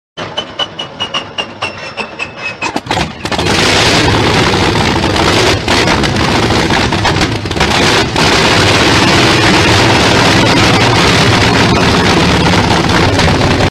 BIG RUSSIAN RADIAL ENGINESTARTUP SOUND sound effects free download